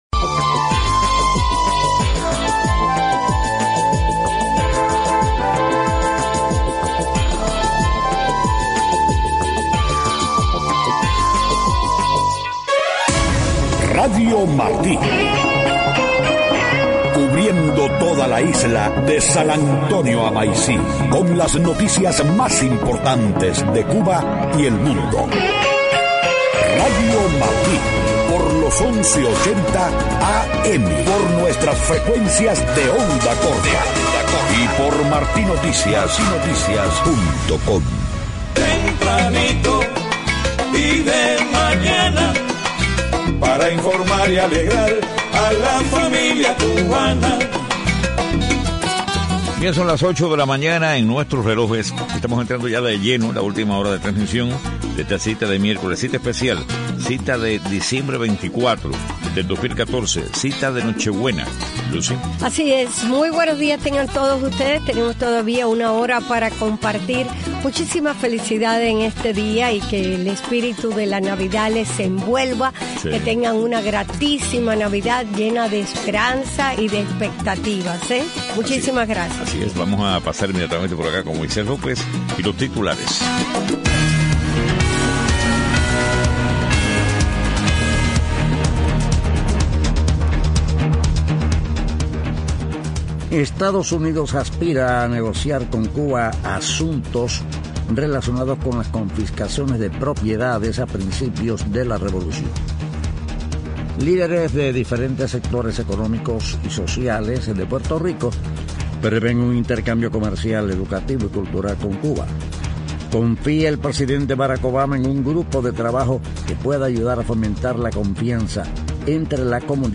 8:00 a.m. Noticias: EEUU aspira negociar con Cuba las confiscaciones de propiedades en los primeros años de la Revolución. Líderes de varios sectores económicos y sociales de Puerto Rico prevén intercambio comercial, educativo y cultural en Cuba.